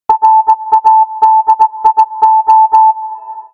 Aké slovo je ukryté v morzeovke?